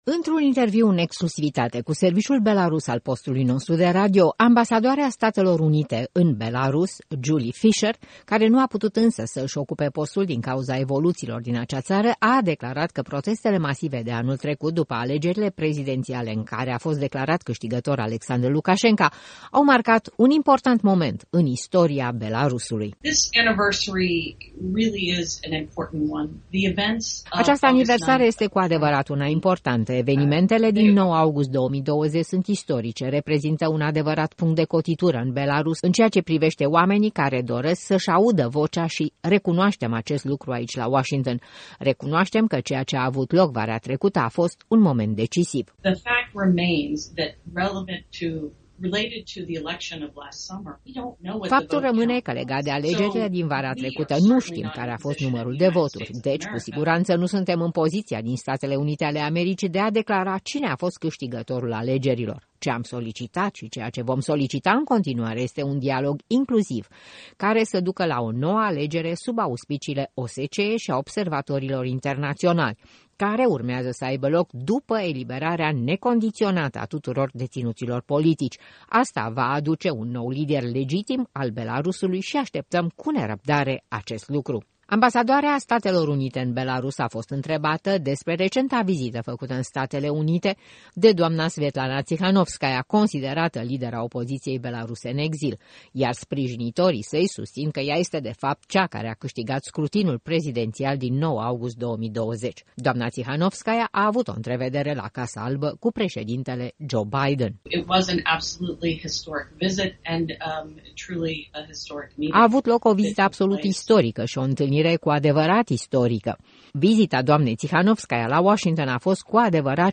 Într-un interviu în exclusivitate cu serviciul belarus al postului nostru de radio, ambasadoarea Statelor Unite în Belarus, care nu a putut însă să își ocupe postul din cauza situației politice din acea țară, a declarant că protestele masive de anul trecut, după alegerile prezidențiale în care a fost declarat câștigător Alexandru Lukașenka au marcat un important moment în istoria acestei țări.